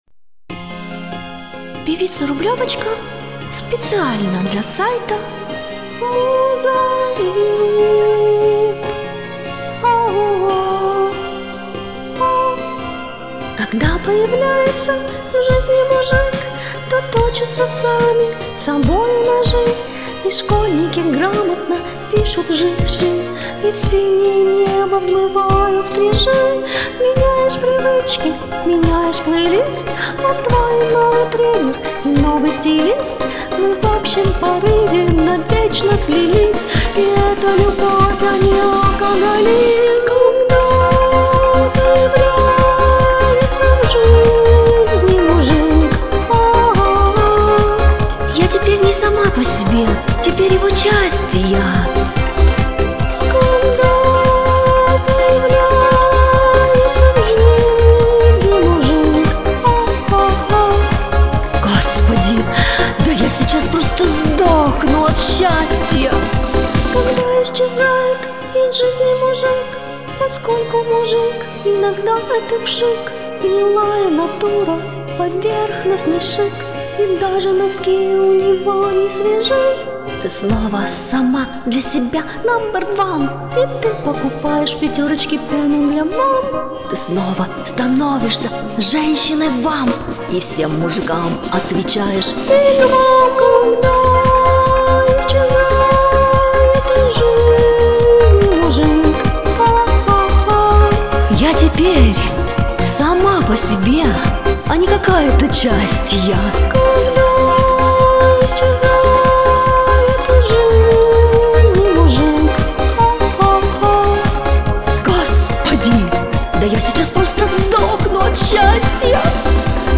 Задорно, легко и по летнему.